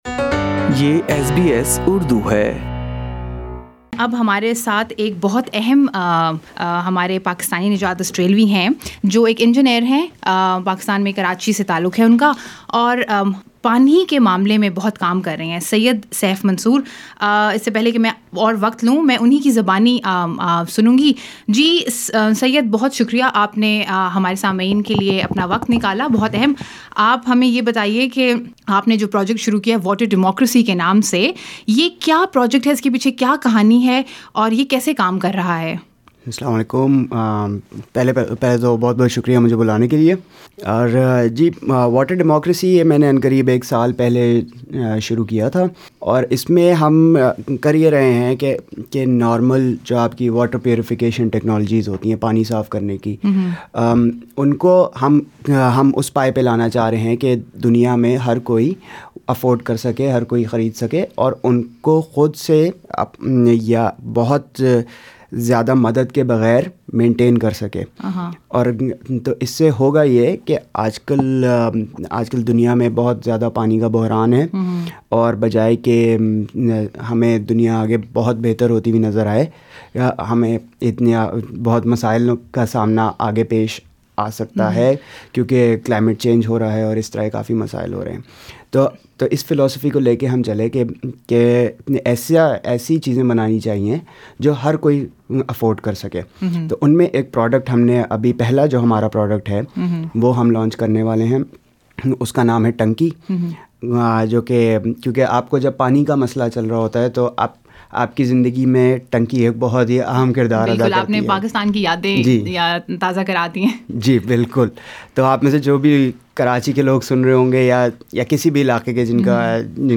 While experts think that the world will be hit by a freshwater scarcity by 2025, and the situation becoming dire by 2050; one Pakistani-Australian engineer believes the catastrophe is to hit us sooner than we think. (Click on the podcast above to listen to the live interview with SBS Urdu).